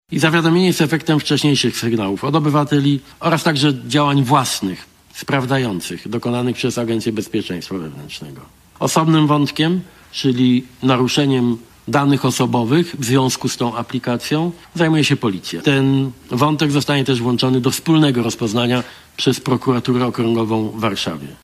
Informację tę podał tuż przed posiedzeniem rządu premier Donald Tusk.